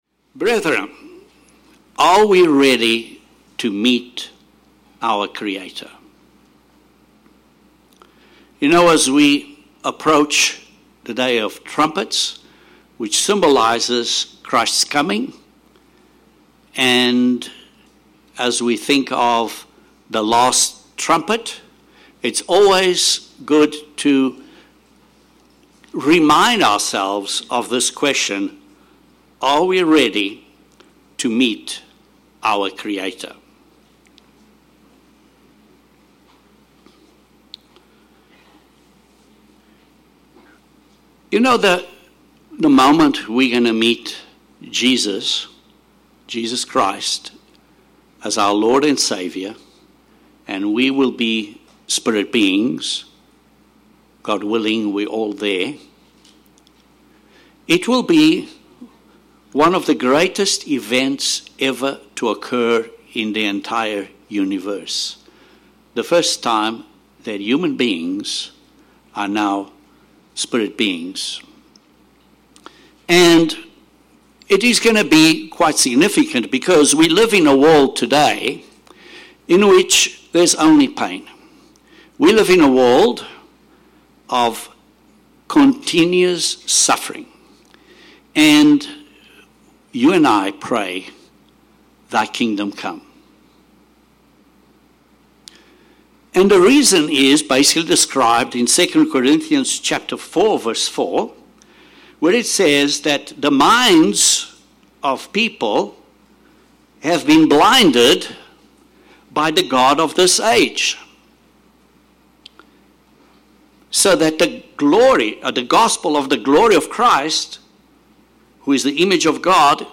This sermon explains the events taking place at an overview level up to His coming, so that we are not caught off guard. Then it highlights what God requires of us to stand with Christ.